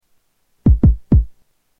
JOMOX XBase09SE Kick
Category: Sound FX   Right: Personal